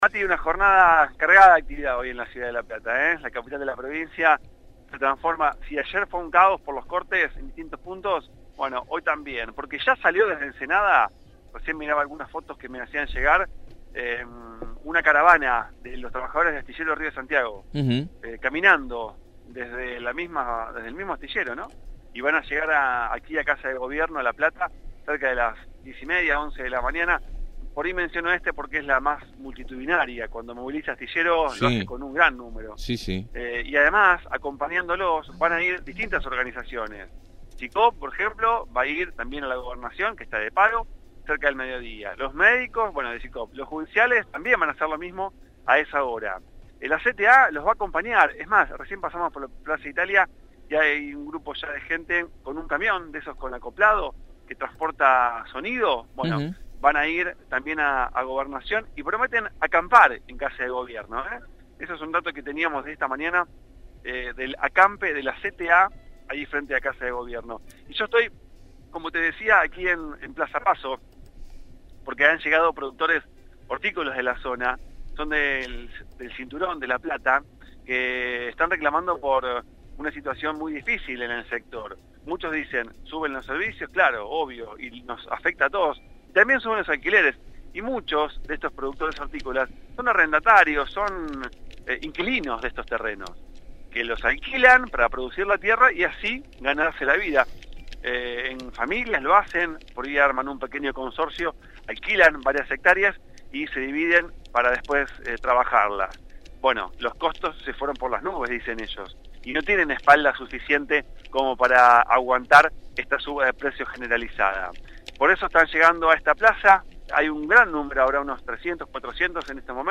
MÓVIL/ Marcha de productores frutihortícolas – Radio Universidad